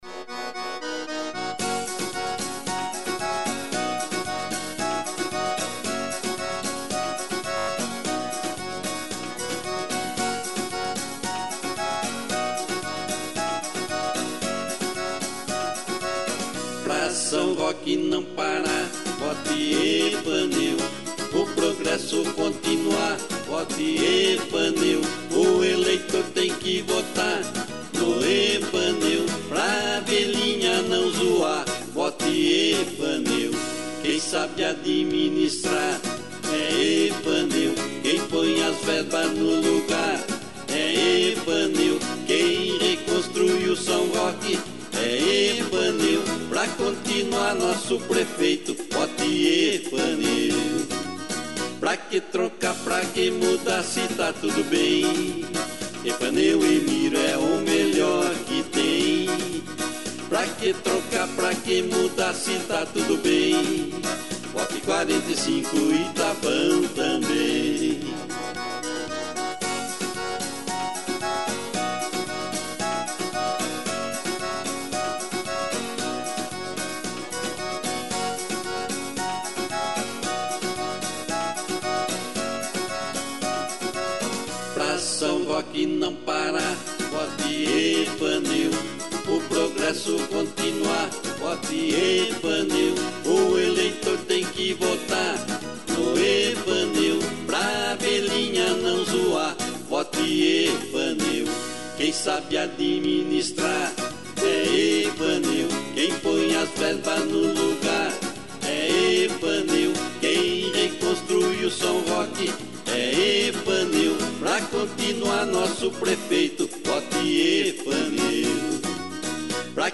Gênero: Blues.